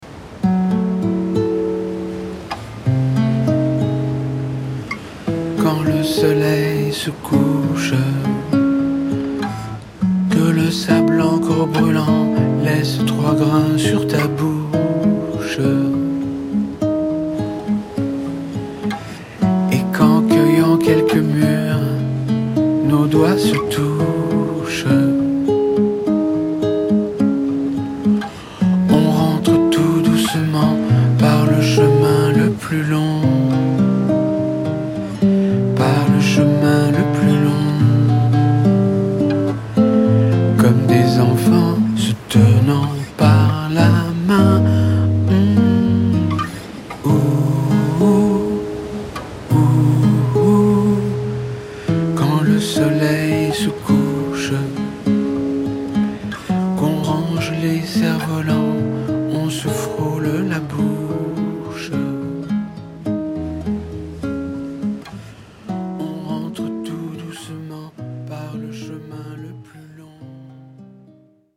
2017 tonalité MI majeur et LAb majeur